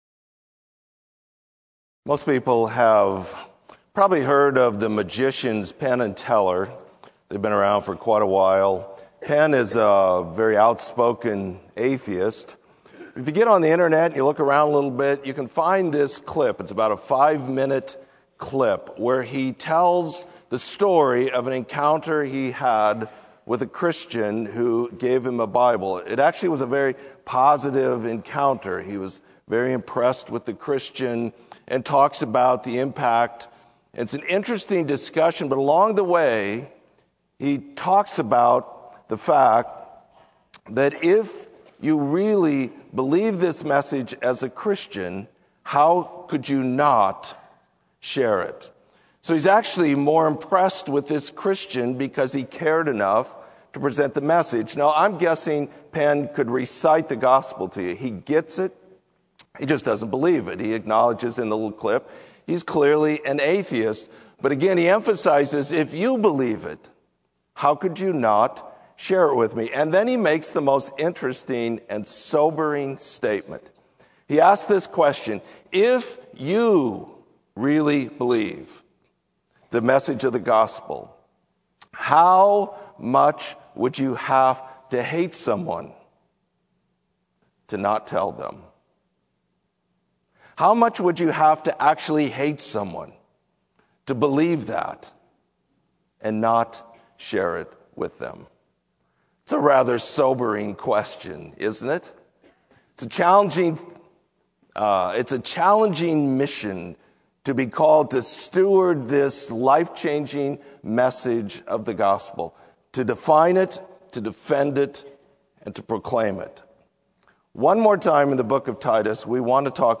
Sermon: Live Like You Believe